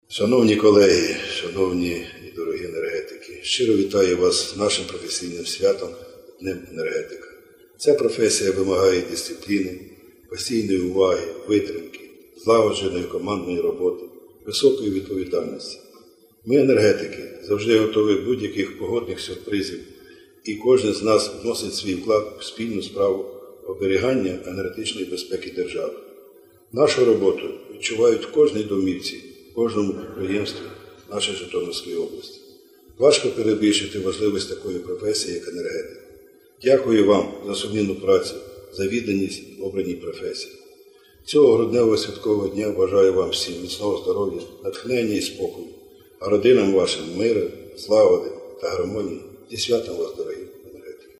Музичне вітання